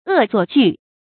惡作劇 注音： ㄜˋ ㄗㄨㄛˋ ㄐㄨˋ 讀音讀法： 意思解釋： 捉弄人的使人難堪的行動。